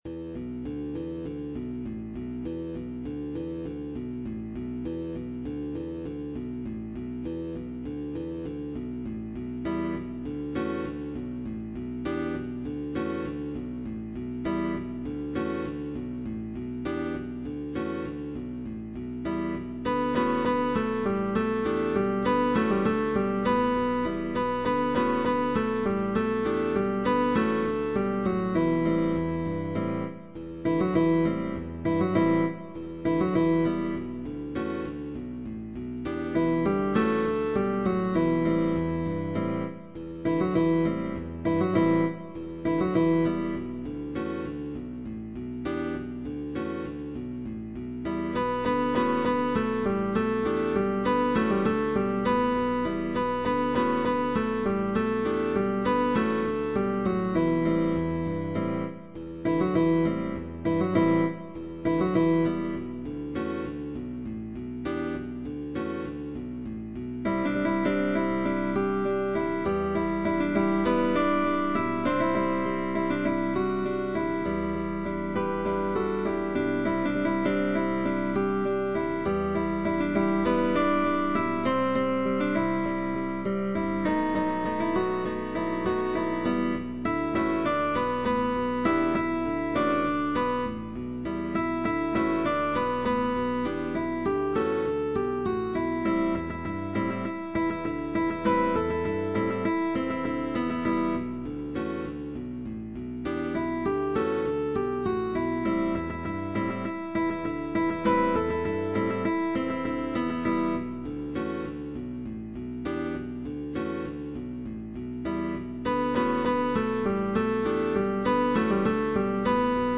Guitar quartet sheetmusic with optional bass.
Bass Guitar optional.
Pop music